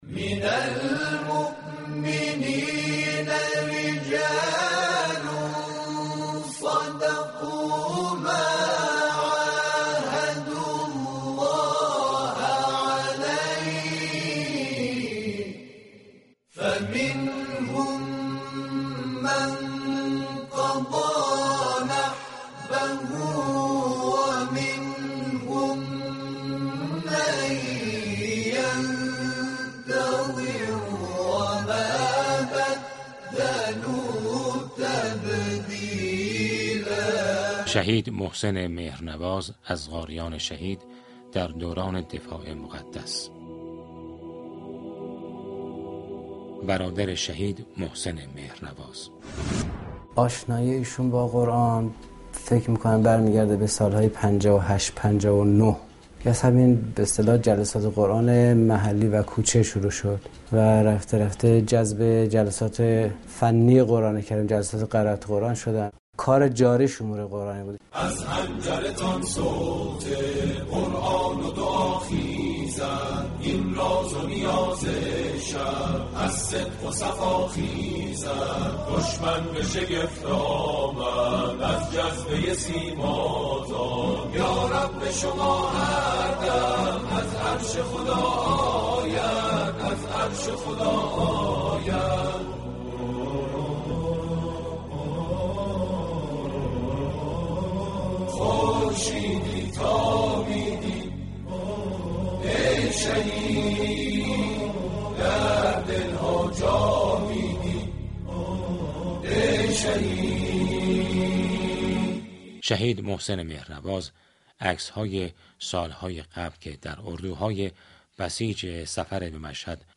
راوی برنامه با خواندن اشعار و جملاتی تأثیرگذار، به پیام جاودانه او اشاره كرد: «رفتند كه نام علی زنده بماند… رفتند كه در این قفس تنگ، پنجره‌ای رو به خدا باز بماند.»
این مستند-روایی كوتاه با تركیب صدا، شعر و خاطره، تصویری زنده از ایمان، عشق به قرآن و آرمان شهادت در دل شنوندگان ترسیم كرد.